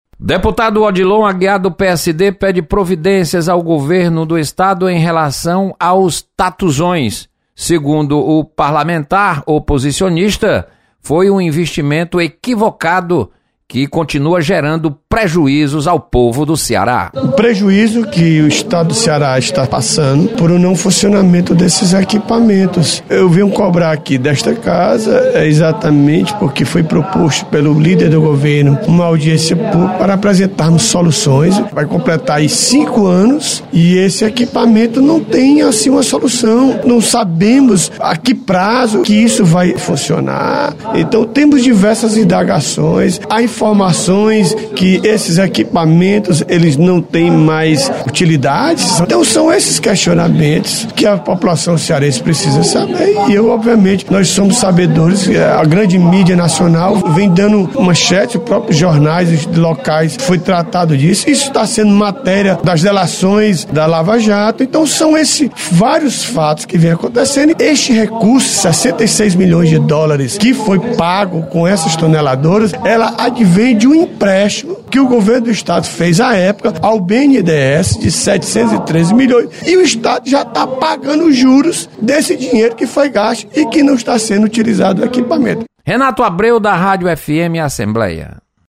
Deputado Odilon Aguiar volta a cobrar explicações sobre as máquinas tuneladoras. Repórter